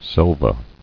[sel·va]